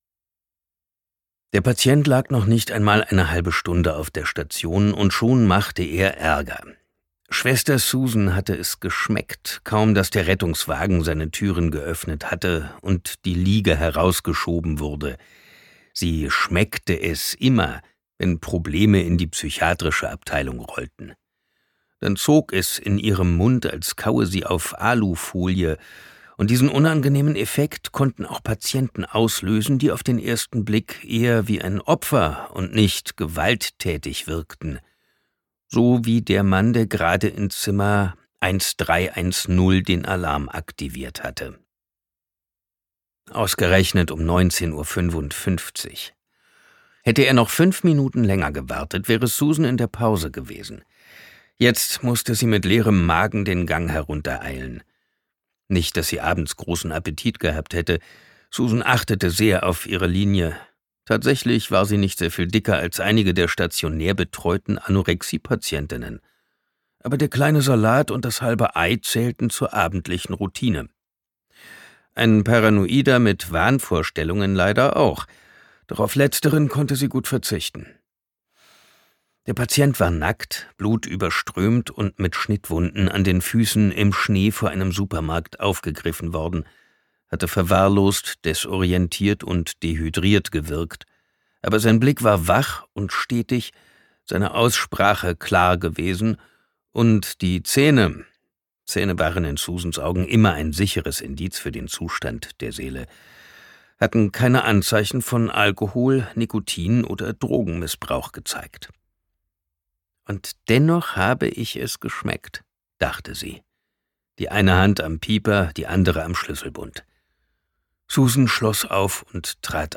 Das Hörbuch Der Nachtwandler von Bestsellerautor Sebastian Fitzek ist ein packender Psychothriller und eines der verstörendsten Thriller-Hörbücher des Autors – intensiv, düster und voller überraschender Wendungen.
Seine markante Stimme hat die Hörbücher entscheidend geprägt und ihren großen Erfolg maßgeblich mitbegründet.
Der Nachtwandler Gelesen von: Sebastian Fitzek, Simon Jäger
• Sprecher:innen: Simon Jäger